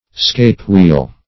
Search Result for " scape-wheel" : The Collaborative International Dictionary of English v.0.48: Scape-wheel \Scape"-wheel`\, n. (Horol.)